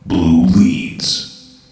blueleads.ogg